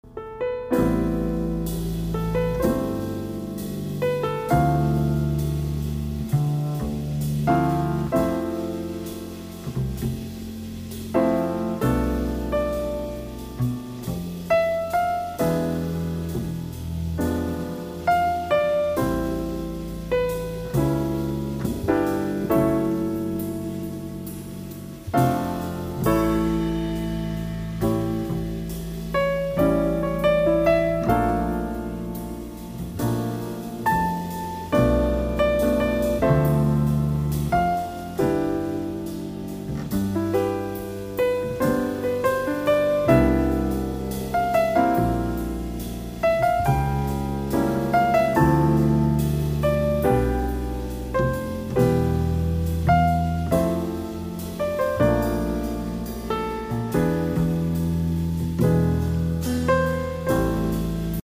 for flute & jazz orchestra